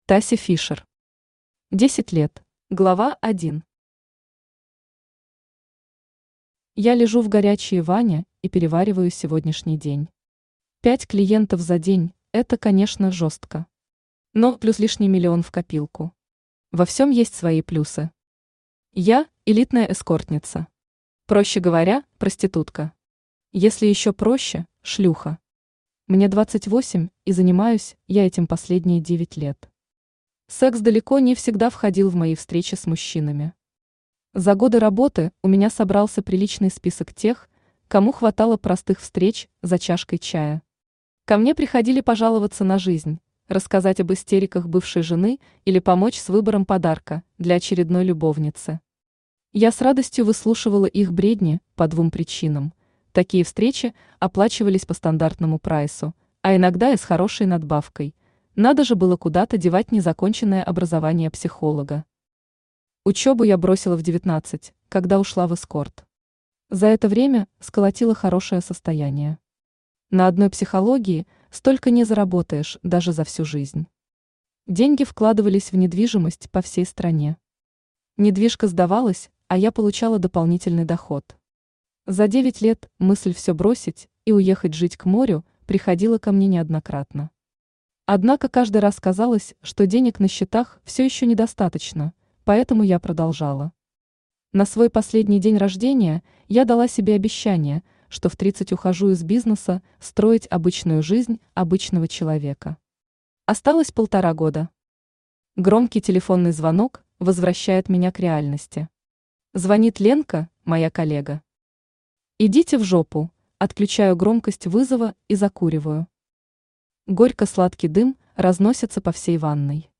Aудиокнига Десять лет Автор Тася Фишер Читает аудиокнигу Авточтец ЛитРес.